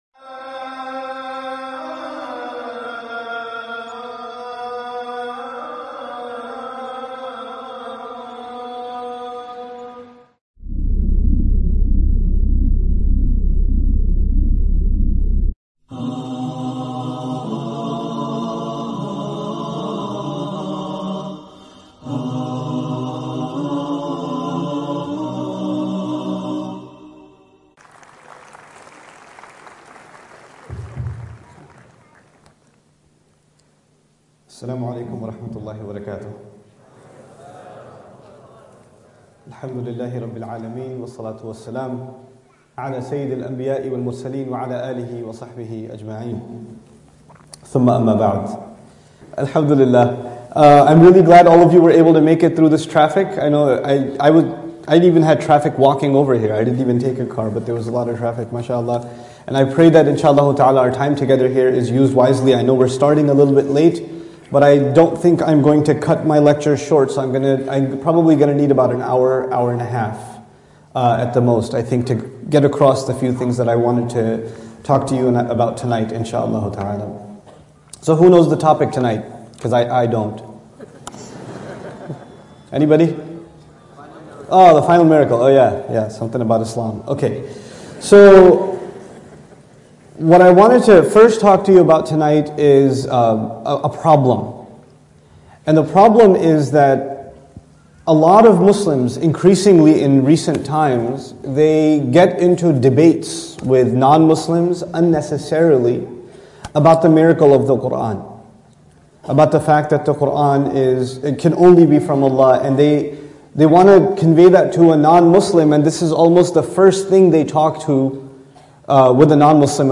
The Miracle of The Quran by Nouman Ali Khan at IIUM during the 2013 Malaysian Tour. Held at the Putra World Trade Centre, Kuala Lumpur on the 6th Sep 2013.